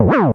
smw_stomp.wav